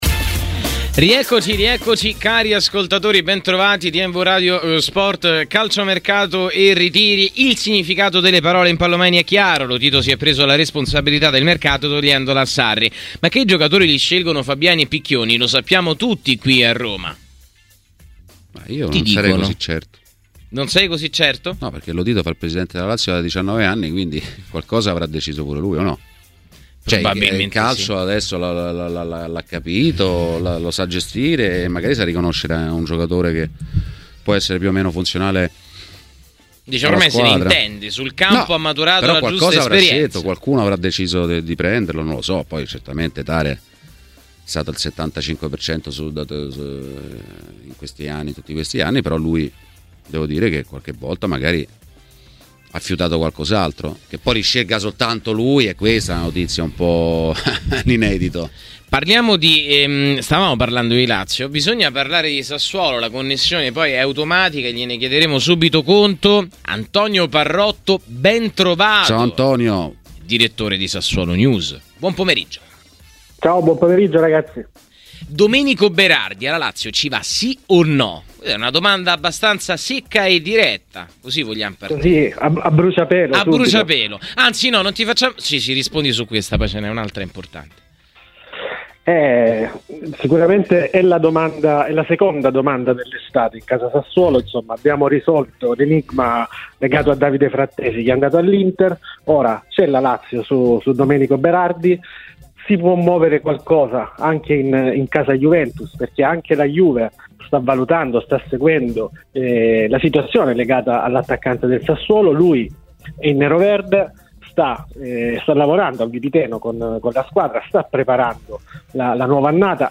Ospite in studio di TMW Radio durante 'Calciomercato e Ritiri'